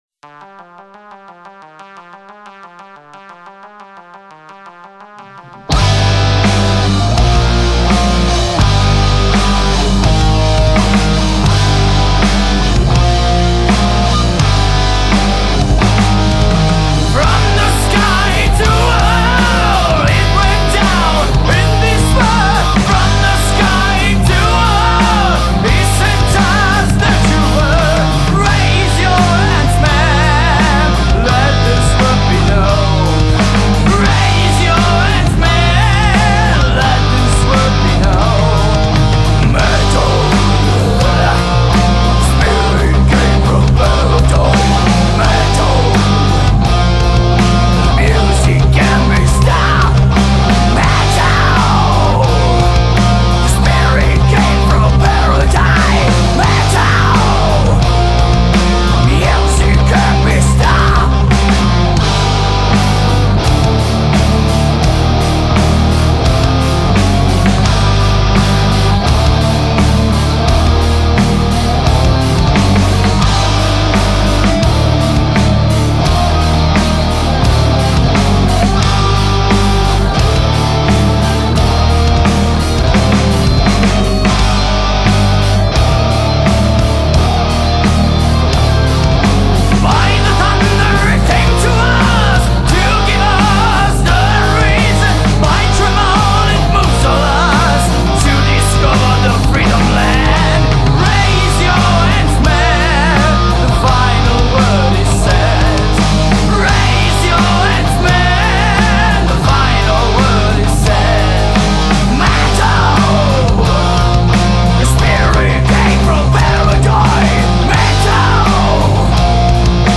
Genere: Metal